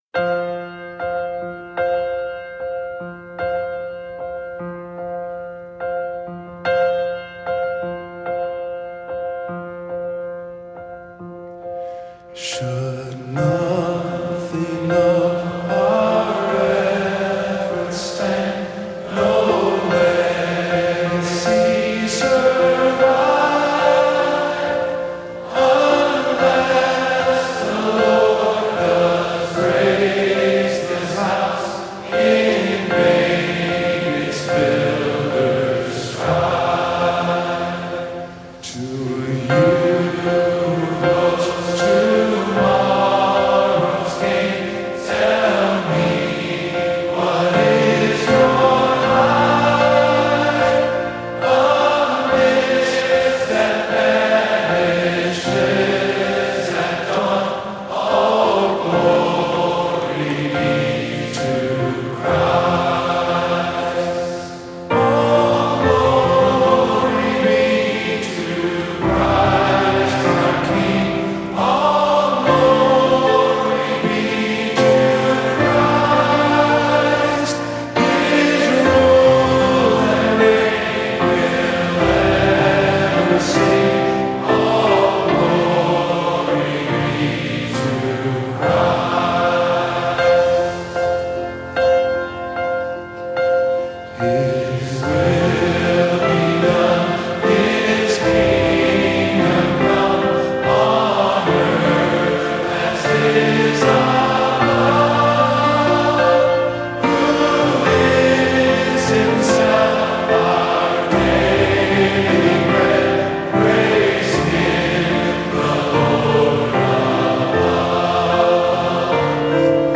All-Glory-Be-to-Christ-Live.mp3